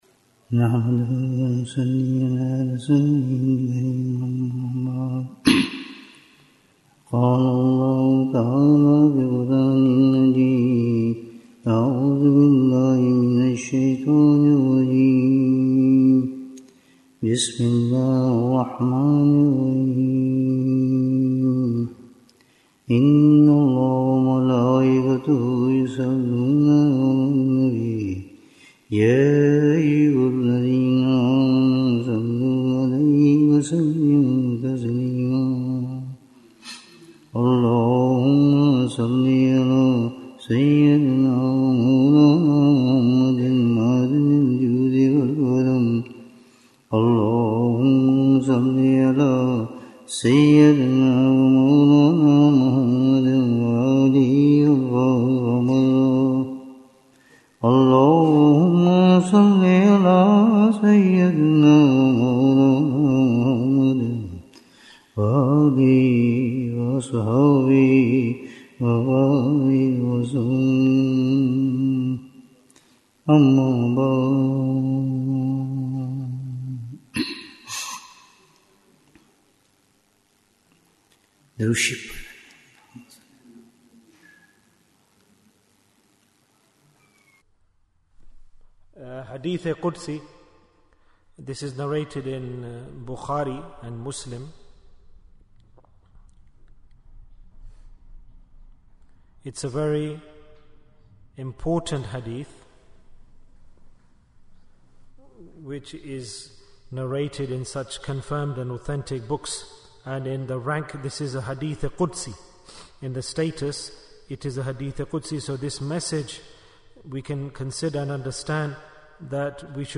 Bayan, 47 minutes 20th March, 2025 Click for Urdu Download Audio Comments Bayan Episode 27 - How Can We Obtain Pure Deen?